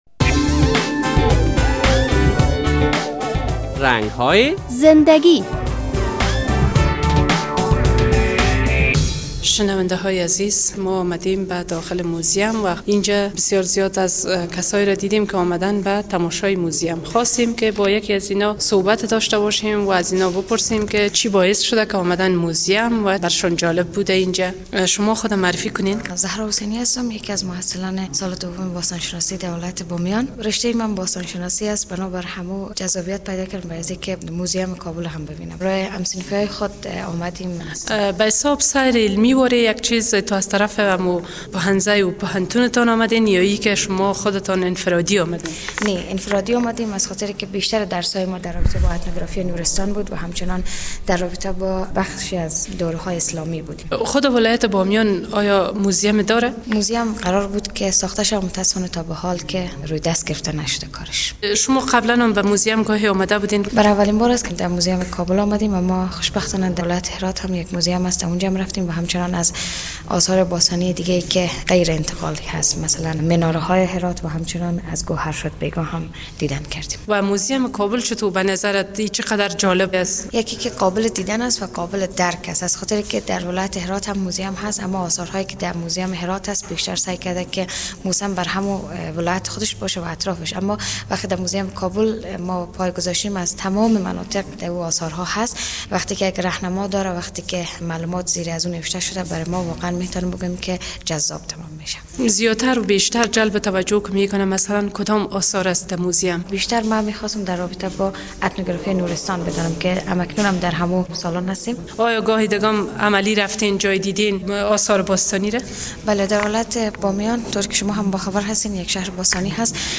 در این برنامهء رنگ های زندگی خبرنگار رادیو آزادی به موزیم ملی افغانستان رفته و با یک تن از افرادیکه مصروف بازدید از آثار این موزیم بود صحبت کرده است.